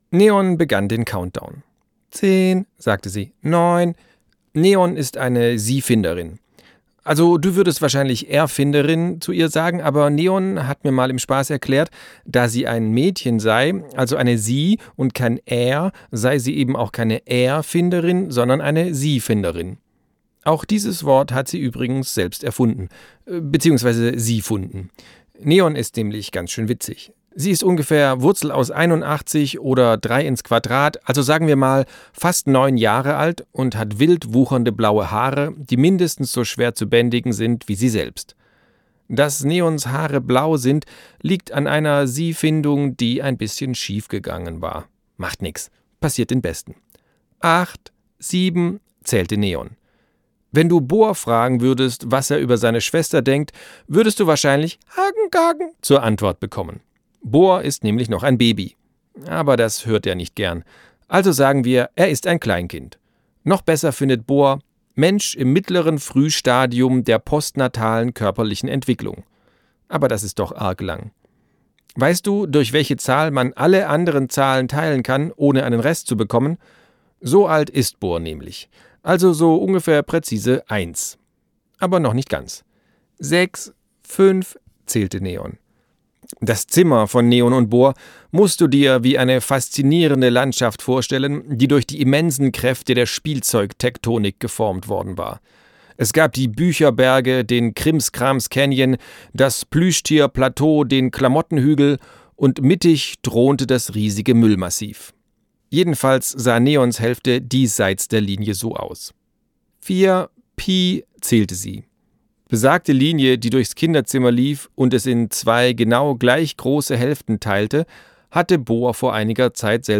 Sprecher Marc-Uwe Kling